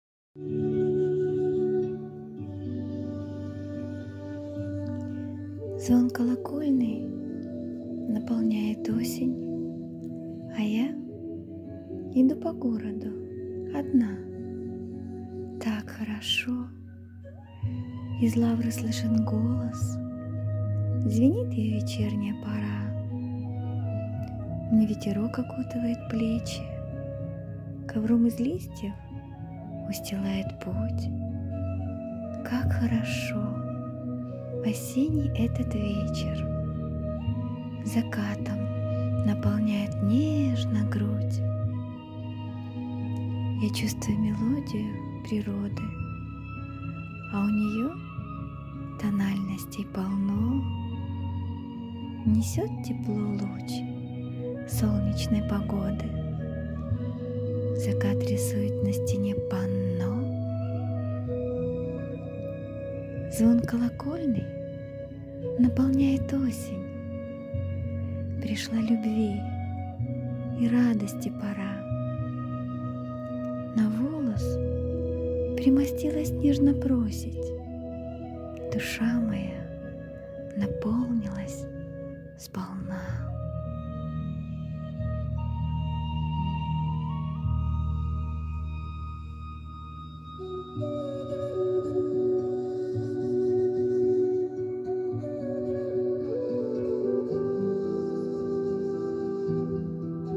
💛🧡❤ ОСЕННЯЯ ЗАРИСОВКА… ( стих начитан)
ВИД ТВОРУ: Вірш